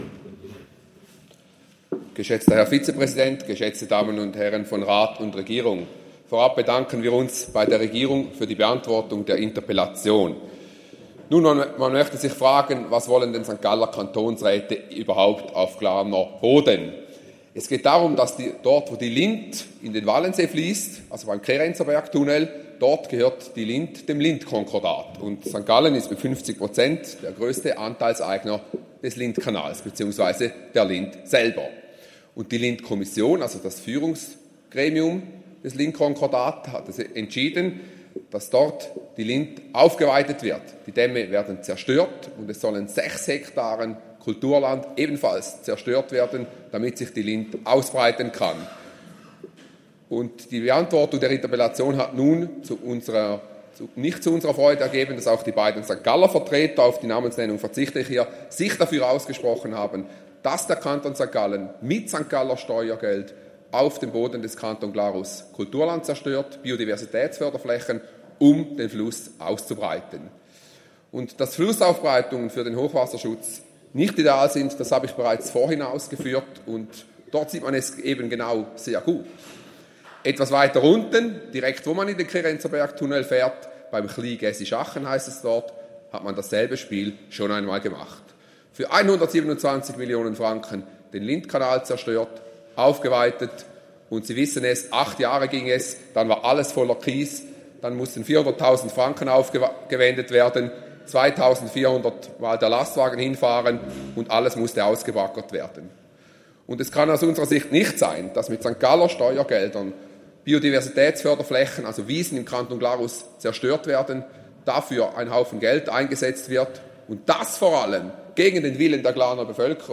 18.9.2024Wortmeldung
Session des Kantonsrates vom 16. bis 18. September 2024, Herbstsession